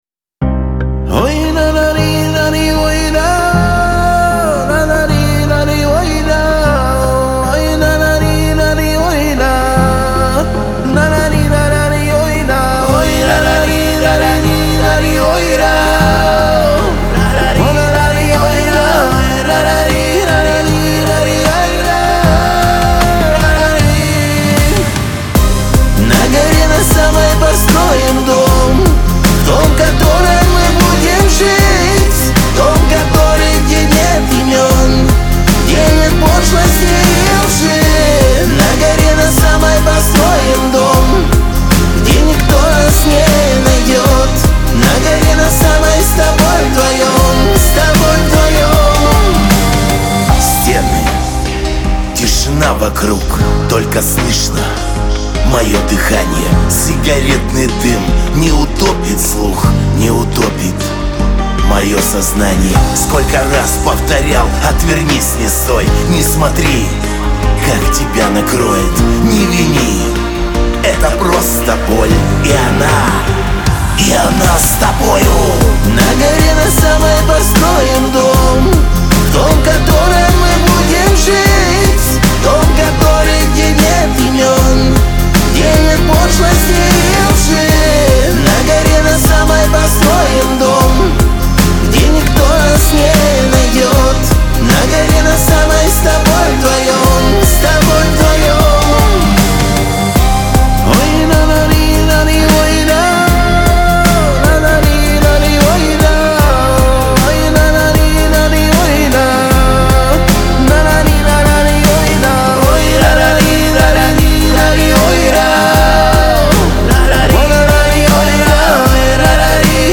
Лирика , диско
Шансон